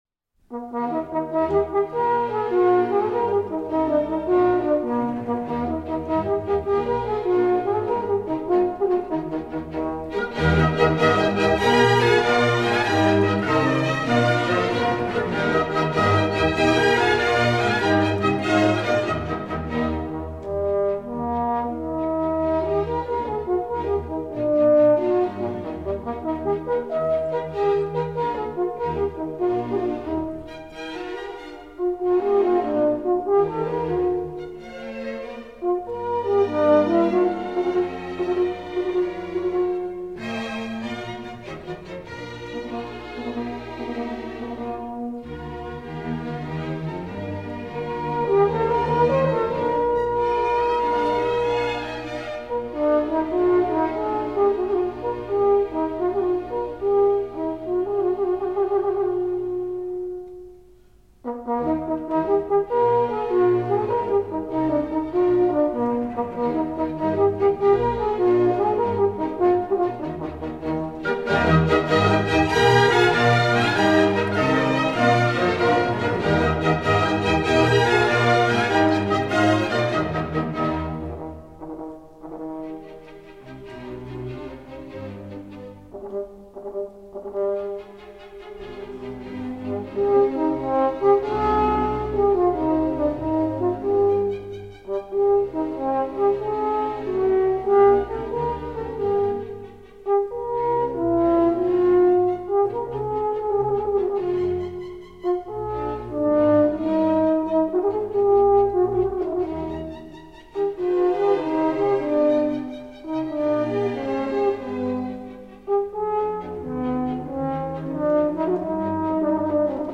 D06-Horn-Concerto-No.-2-in-E-flat-maj.mp3